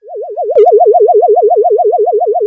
UFO06.wav